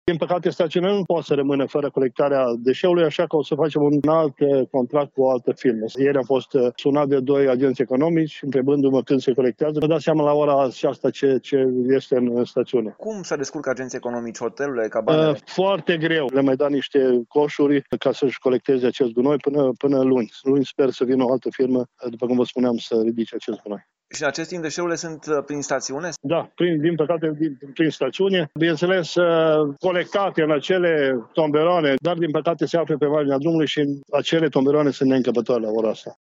La rândul său, primarul comunei Moneasa, Ioan Nuțu Herbei spune că stațiunea este sufocată de gunoaie și că situația de urgență trebuie instituită de cel puțin 6 luni.